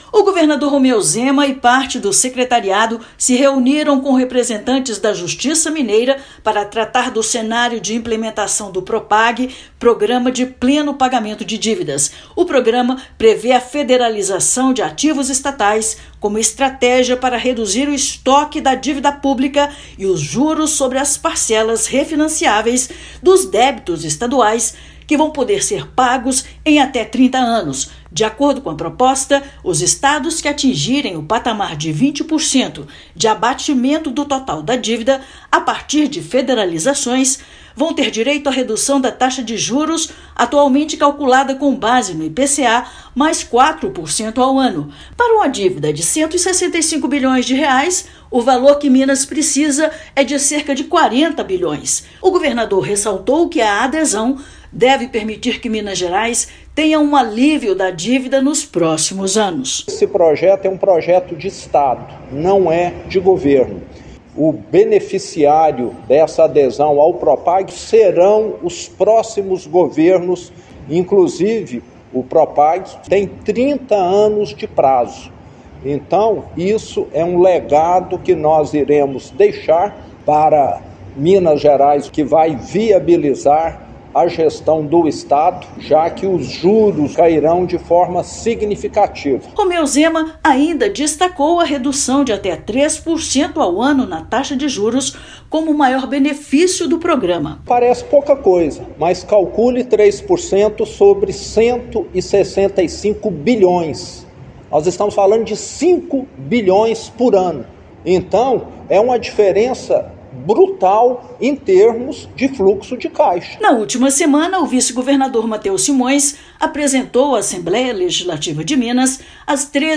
[RÁDIO] Governo de Minas se reúne com instituições de Justiça para tratar da adesão ao Propag
Governador apresentou benefícios que o Estado terá a partir da implementação do programa de redução da dívida com a União. Ouça matéria de rádio.